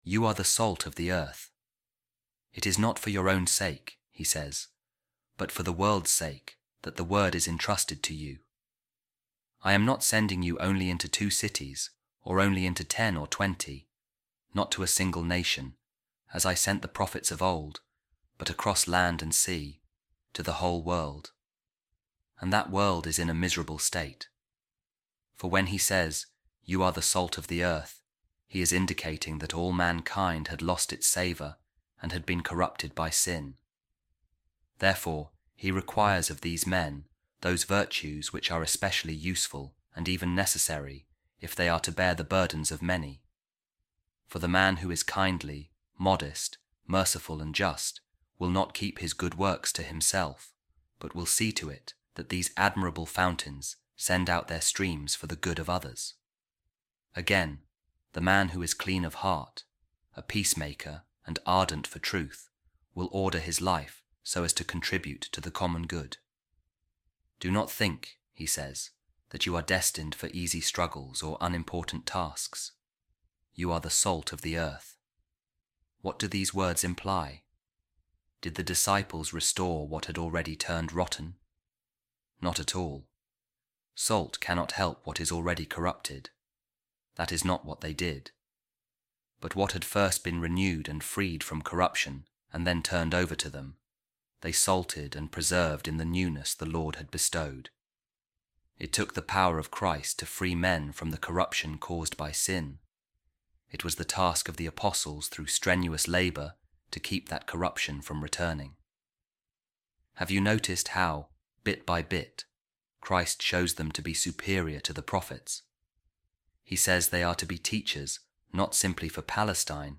A Reading From The Homilies Of Saint John Chrysostom On Saint Matthew’s Gospel | Salt Of The Earth And Light Of The World